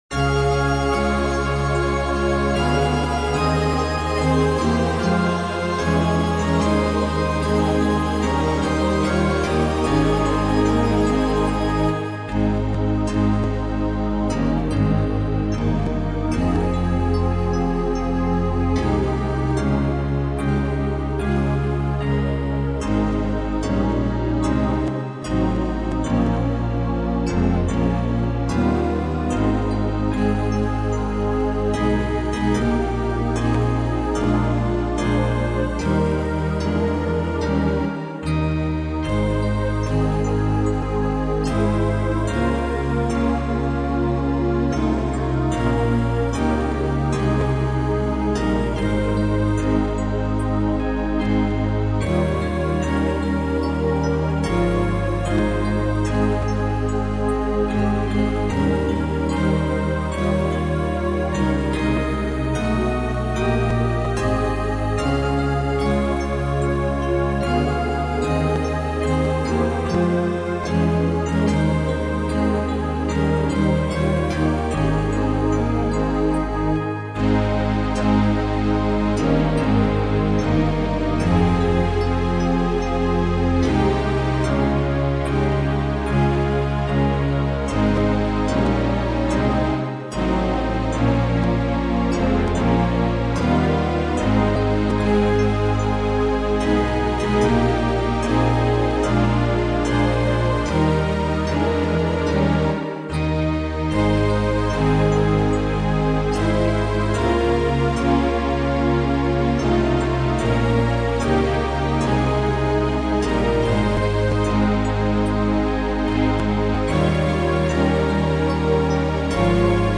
Instrumental
Alay ng Puso (No Lyrics).mp3